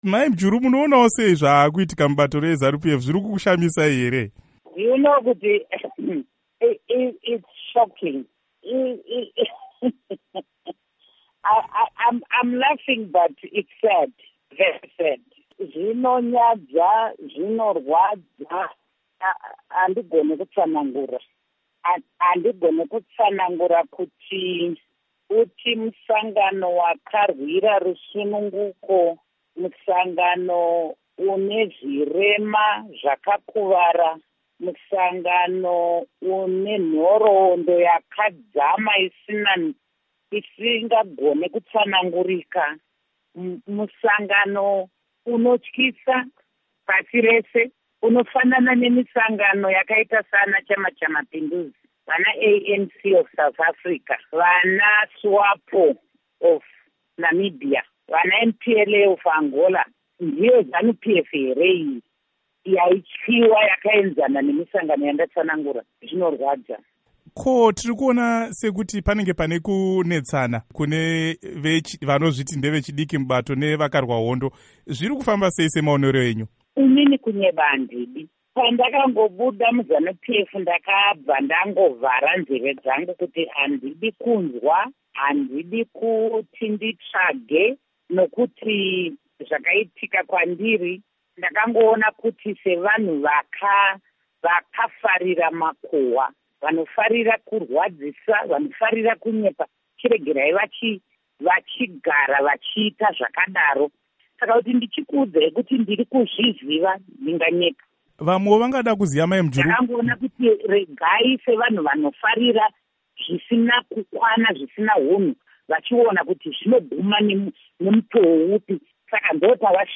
Hurukuro naAmai Joice Mujuru